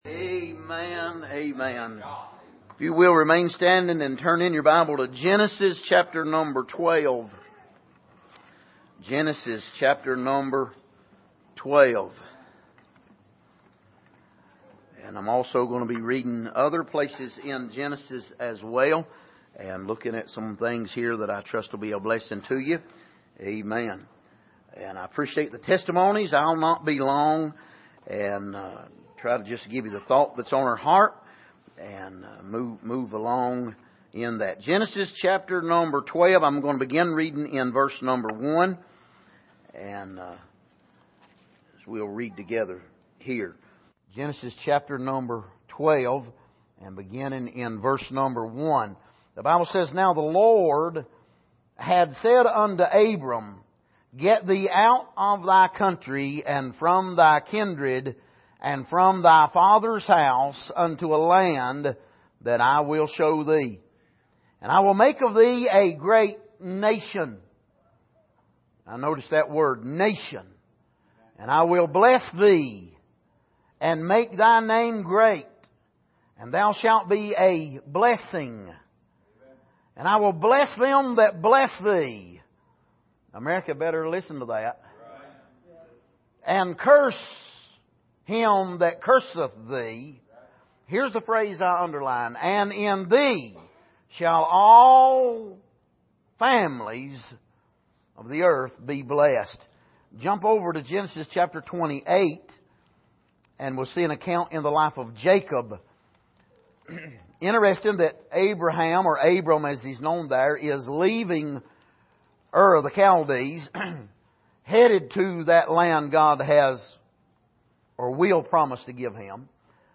Passage: Genesis 12:1-3 Service: Sunday Morning